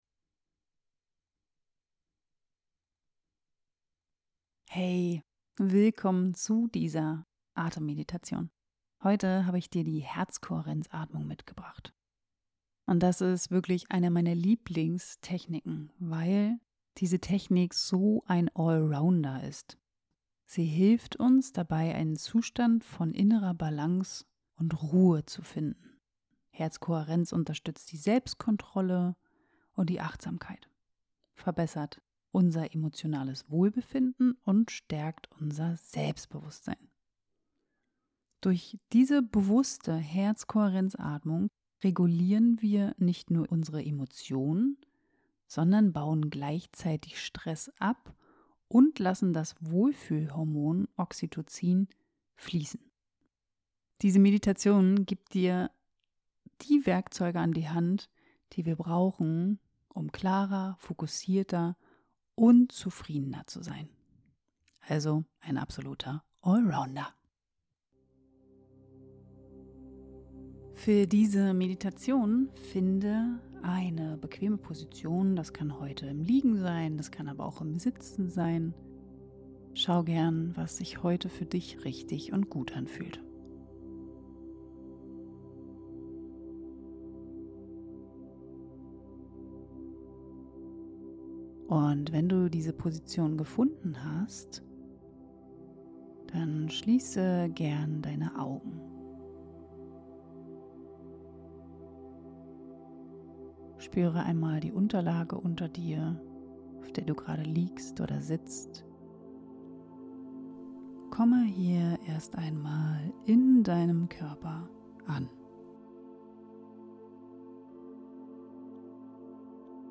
Diese Atemmeditation führt dich sanft in die Herz-Kohärenz – einen Zustand, in dem Herzschlag, Atmung und Gehirnaktivität im Einklang schwingen.